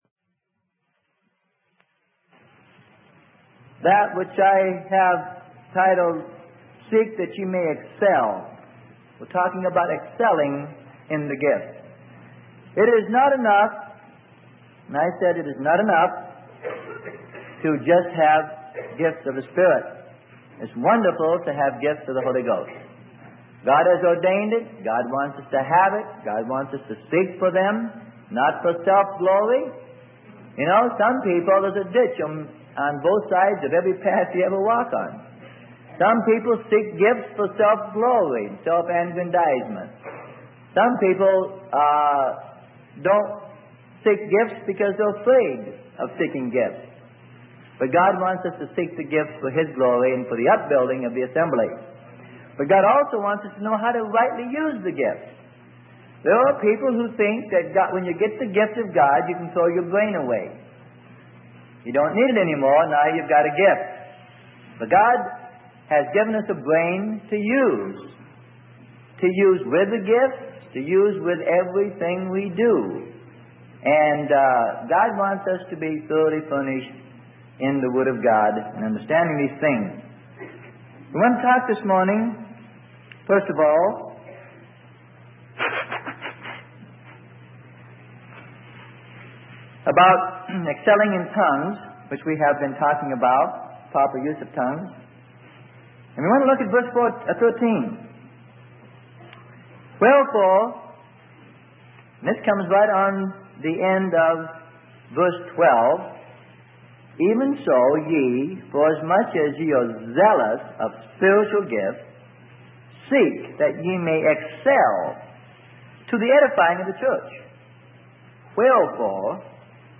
Sermon: Seek That You May Excel - Part 2 - Freely Given Online Library